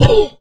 MONSTERS_CREATURES
MONSTER_Cough_01_mono.wav